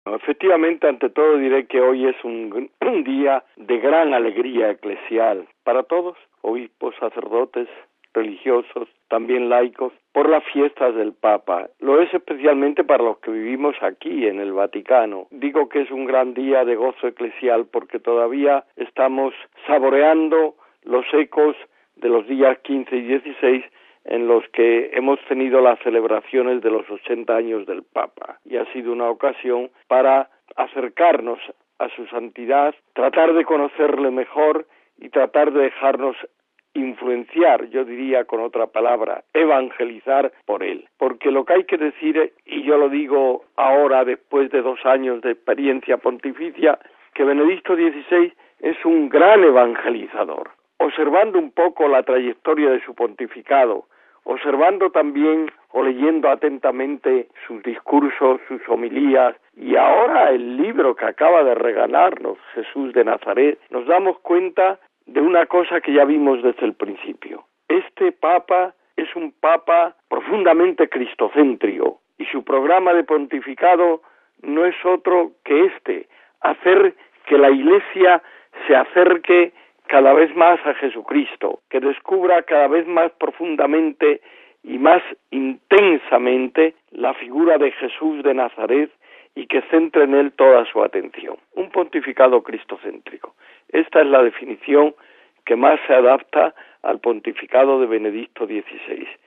Seguidamente les ofrecemos el testimonio del Obispo Cipriano Calderón, vicepresidente emérito de la Pontificia Comisión Para América Latina, sobre como ha vivido y visto estos dos años de pontificado de Benedicto XVI: RealAudio